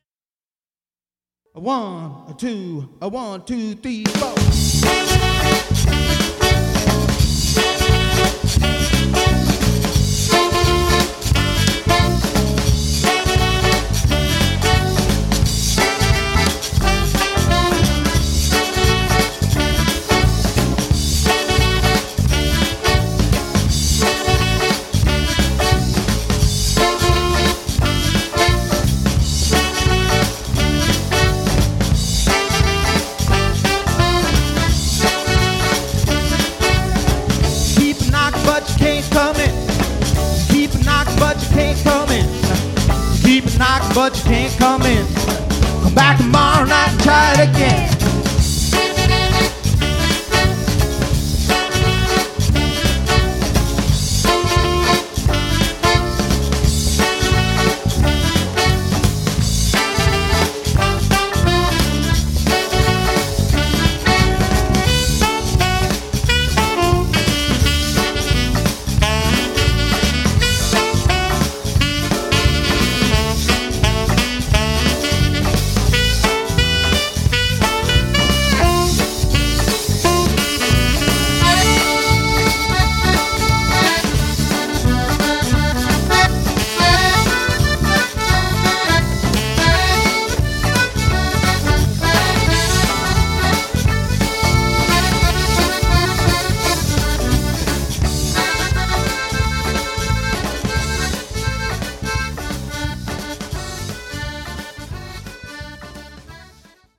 accordions, saxophones and vocals
alto saxophone, rubboard and vocals
bass and vocals
drums